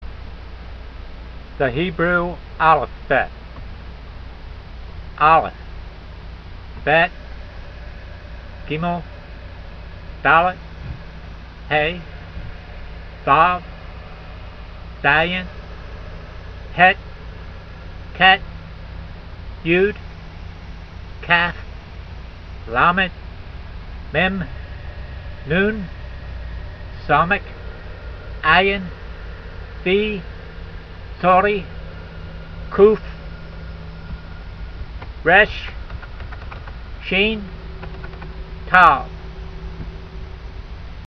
The tsawdi may have more of an “s” sound than the recording